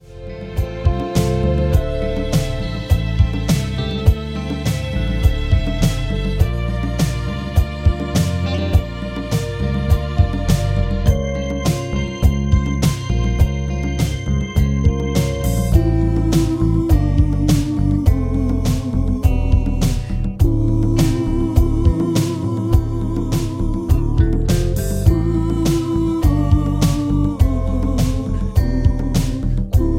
Gb
Backing track Karaoke
Pop, 1970s